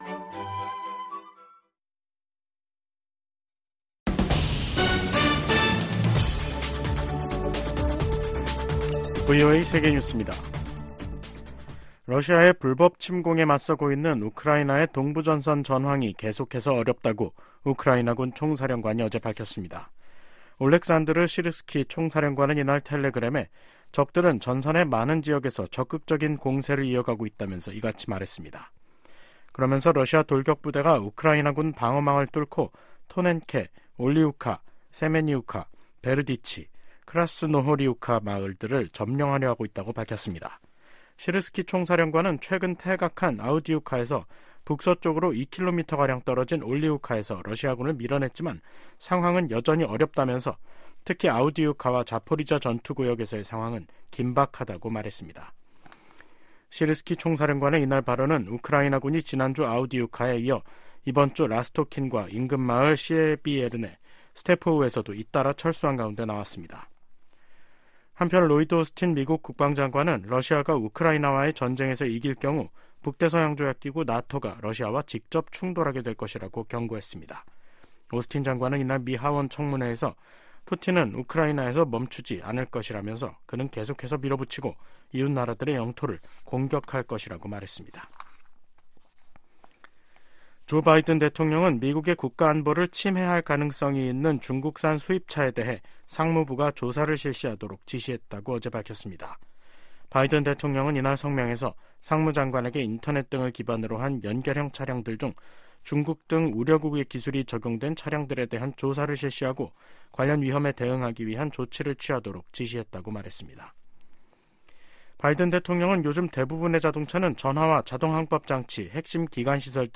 VOA 한국어 간판 뉴스 프로그램 '뉴스 투데이', 2024년 3월 1일 2부 방송입니다. 북한이 미국의 우주 체계를 위협할 사이버전과 전자전 역량을 갖췄다고 미군 고위 당국자들이 평가했습니다. 윤석열 한국 대통령이 제105주년 3.1절 기념식에서 북한에 자유와 인권을 확장하는 게 통일이라고 말했습니다. 커트 캠벨 미 국무부 부장관이 방미 중인 조태열 한국 외교부 장관을 만나 북한 문제와 북-러시아 무기거래, 미한동맹 강화 등을 논의했습니다.